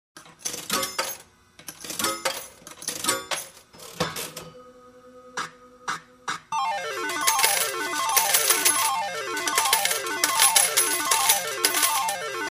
igrovoj_avtomat.mp3